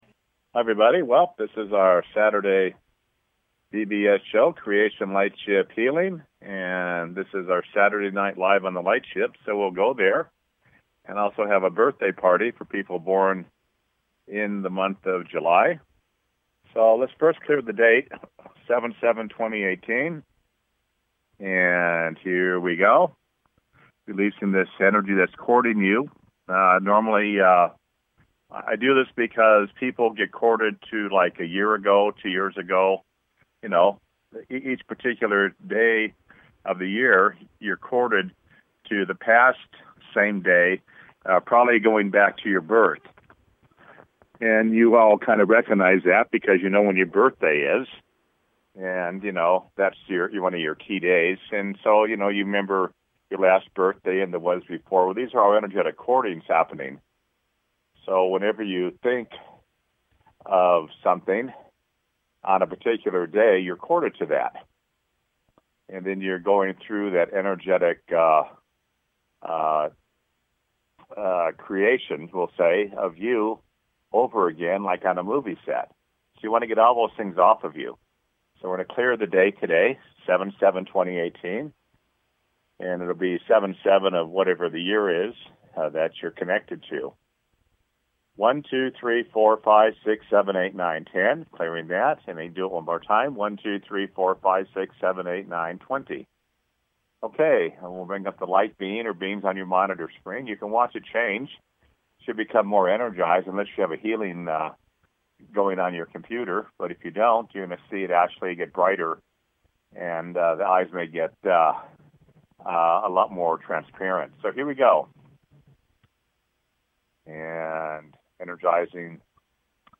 Tune into the Creation Lightship Healing BBS Radio Show to experience this Divine Lightship and its teachings.